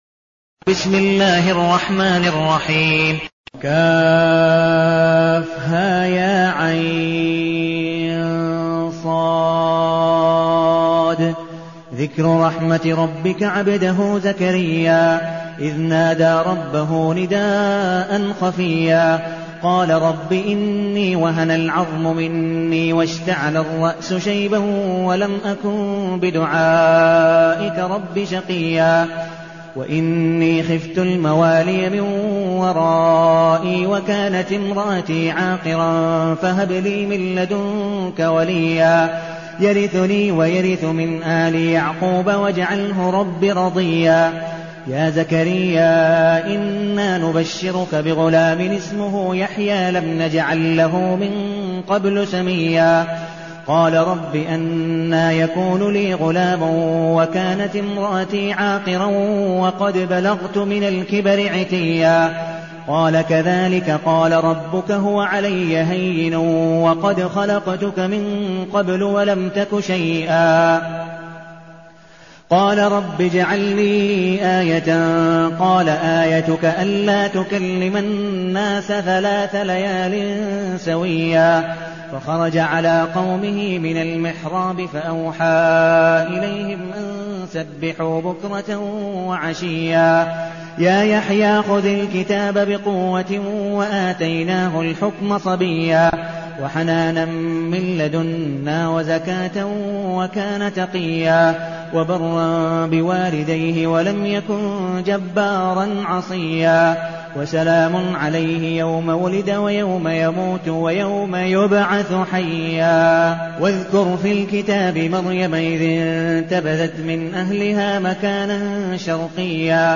المكان: المسجد النبوي الشيخ: عبدالودود بن مقبول حنيف عبدالودود بن مقبول حنيف مريم